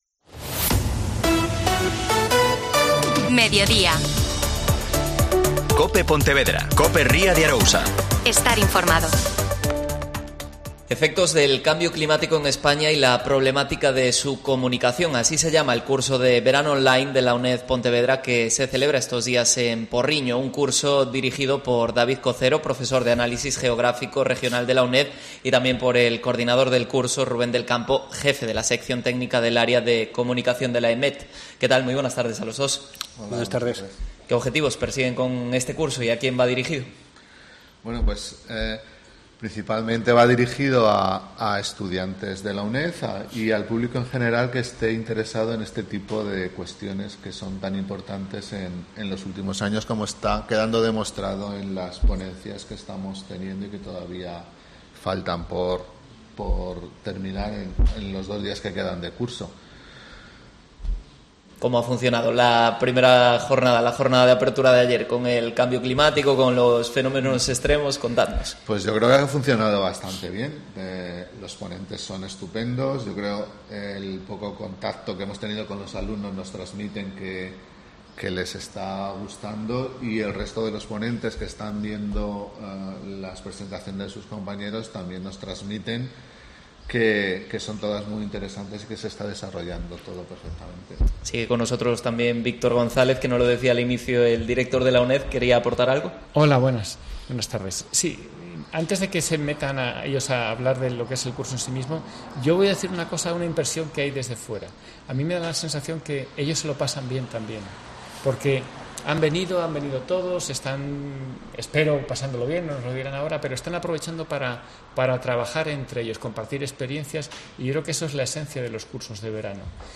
Programa Especial desde el Aula Universitaria de la UNED Pontevedra en O Porriño.